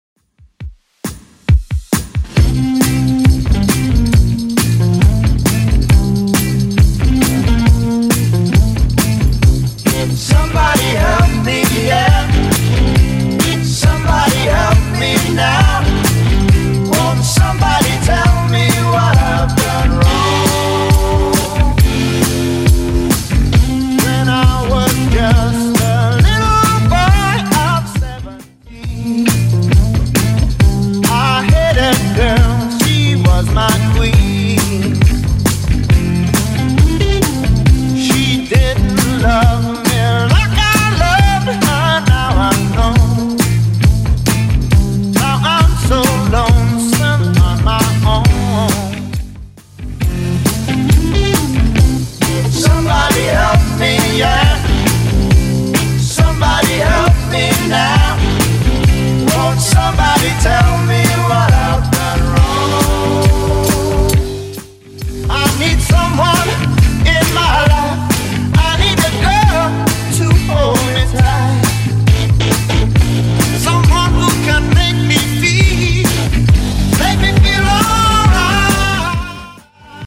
Genre: 80's
BPM: 105